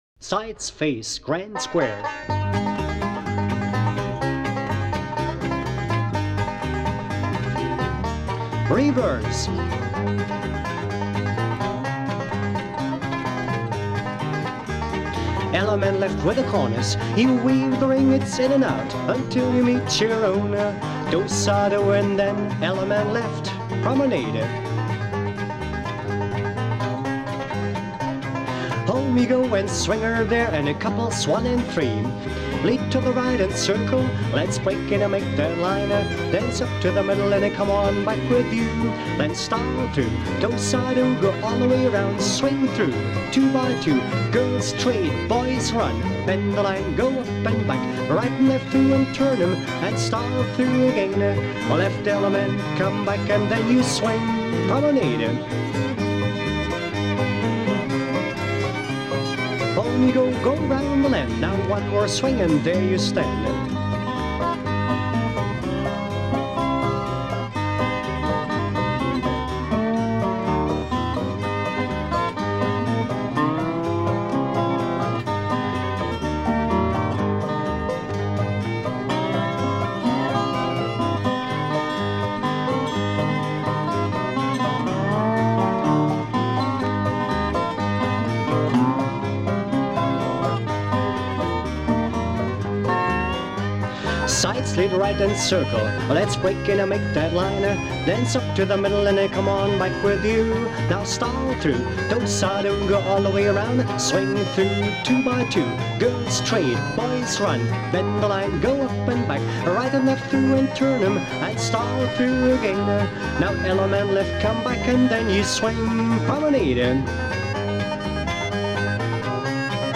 folk group
Fiddle
Guitar
Banjo, Dobro
Bass
Square dance calls
Bandoneon